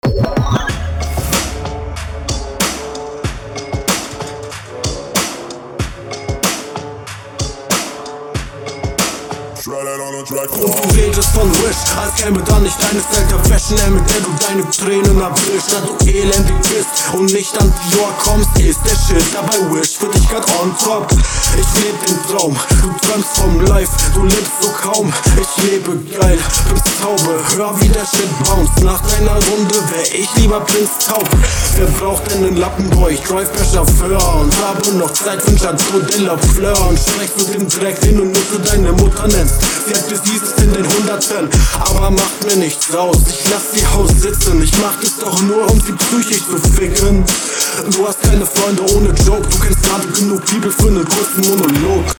Man versteht dich einfach sehr schwer. Du flowst unverständlich. Es wirkt einfach übelst vernuschelt.